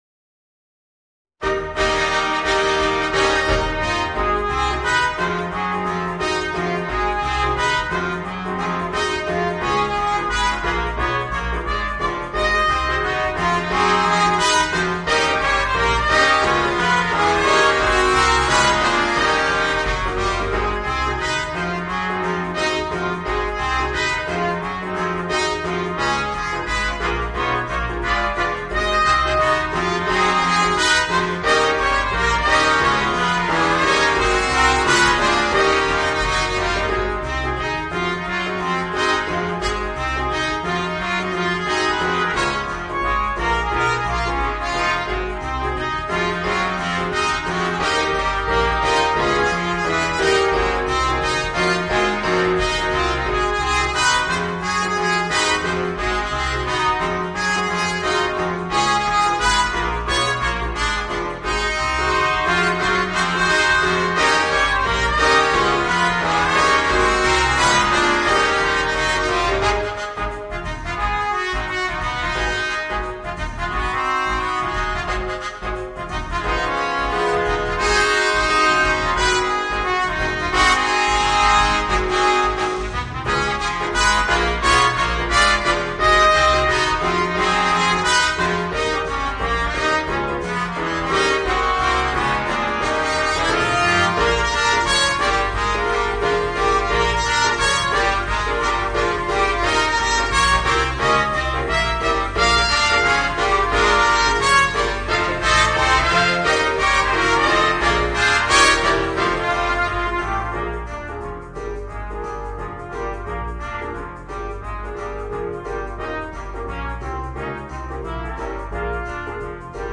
Voicing: 4 Trumpets